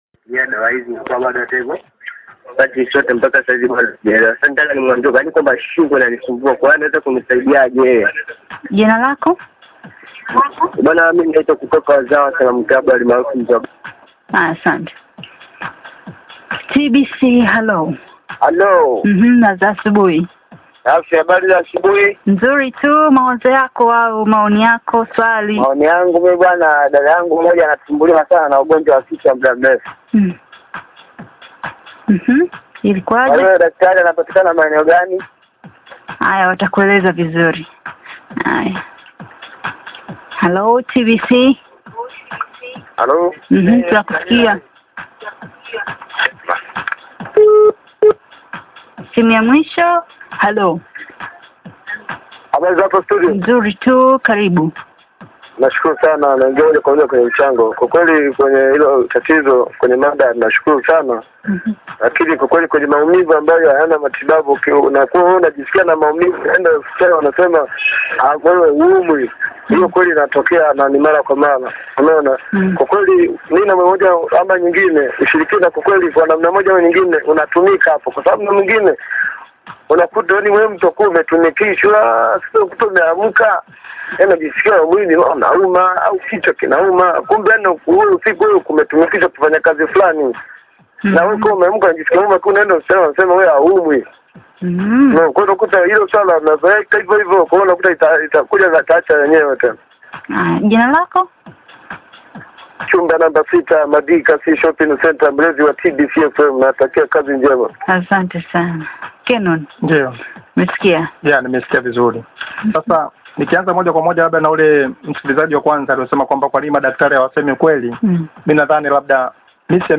answers questions from callers on TBC Radio show